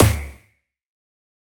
taiko-normal-hitfinish.ogg